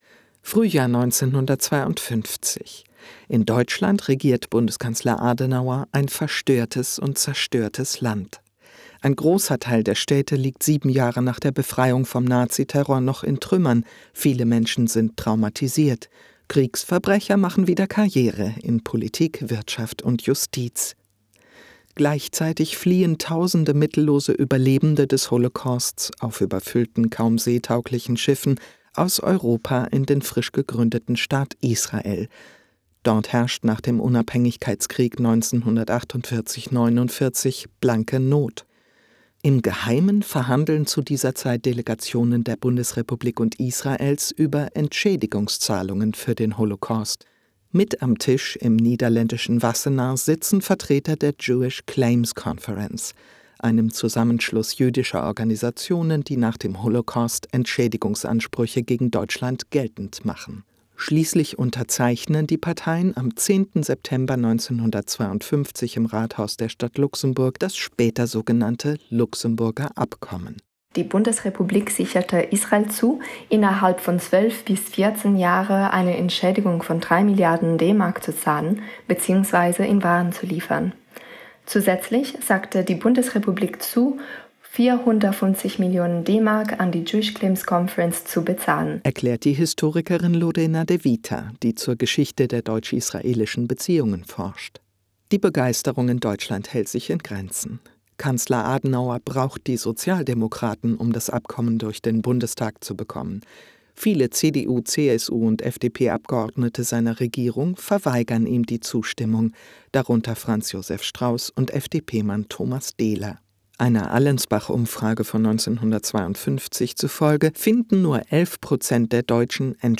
Hier gibt es meinen Deutschlandfunk-Radiobeitrag zum Nachhören: